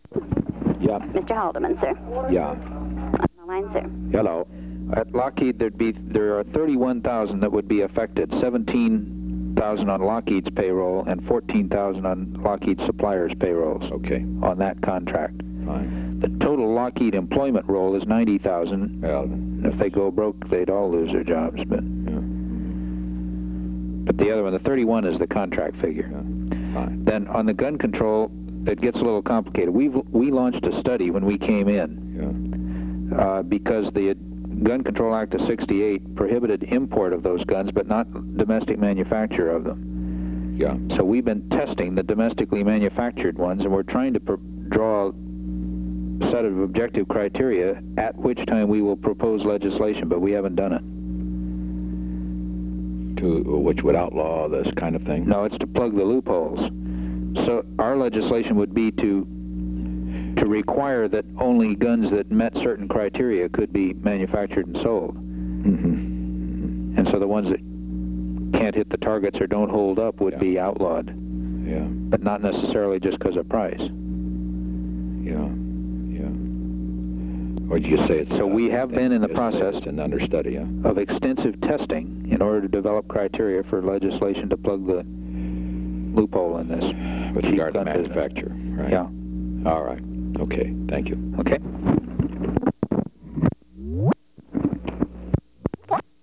Location: White House Telephone
H.R. (“Bob”) Haldeman talked with the President.